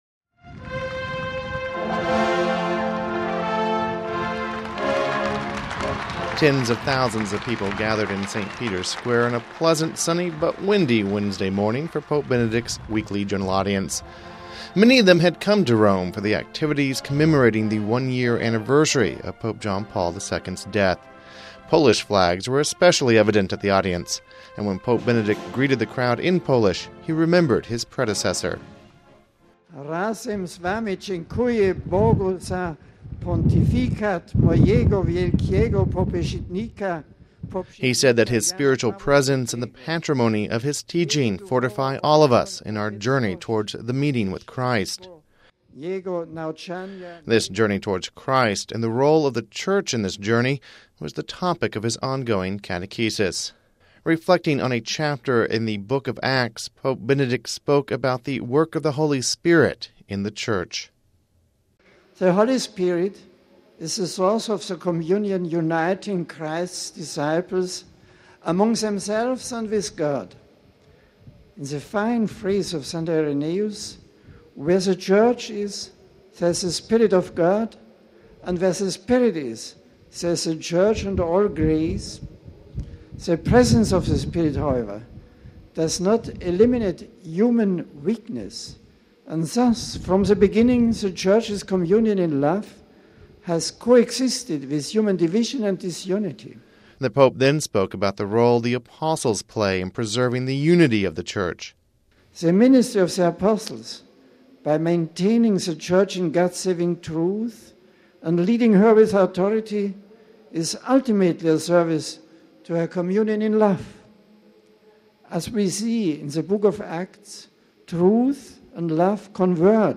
(05 Apr 2006 RV) Communion within the Church was the theme of Pope Benedict XVI's catechesis during his general audience, held this morning in St. Peter's Square in the presence of 30,000 people.